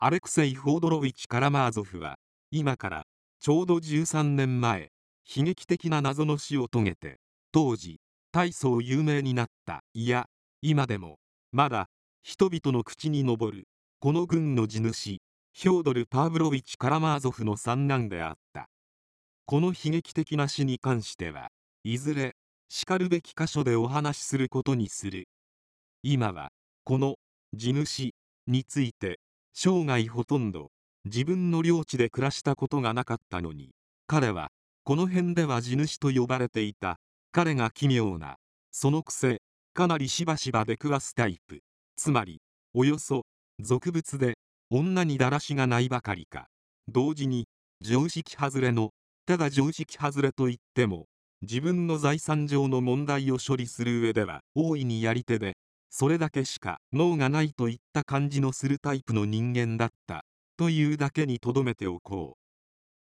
電子書籍や、音声朗読機能を使ってみるのも、良さそうなので、試しに作ってみました。
関西弁